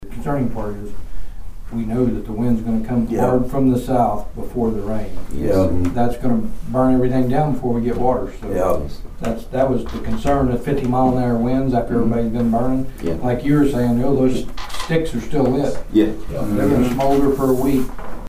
District Three Commissioner Troy Friddle discussed the importance of the burn ban.
Friddle on Burn Ban Importance.mp3